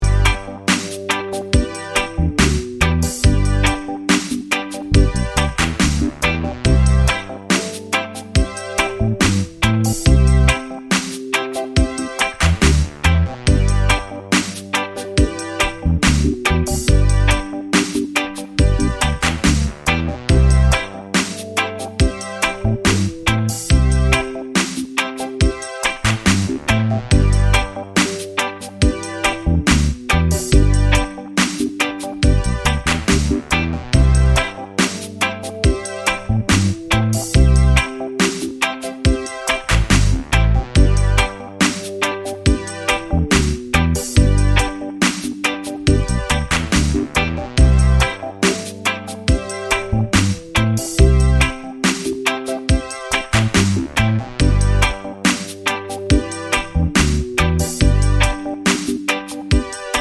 no Backing Vocals Reggae 5:07 Buy £1.50